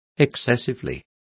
Complete with pronunciation of the translation of excessively.